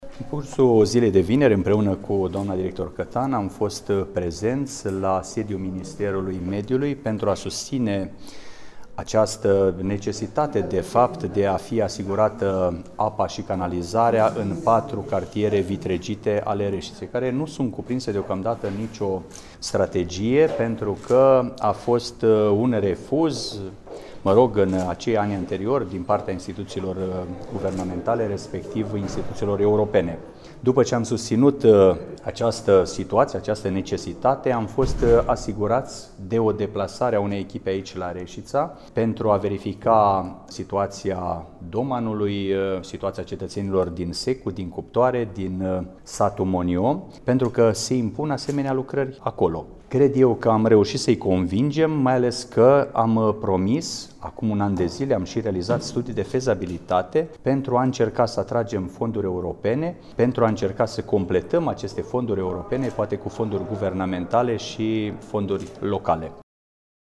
Prioritare sunt cartierele Secu, Cuptoare, Doman şi Monion, a precizat astăzi, în cadrul şedinţei Consiliului Local Reşiţa, primarul Mihai Stepanescu.
Primarul Reşiţei, Mihai Stepanescu: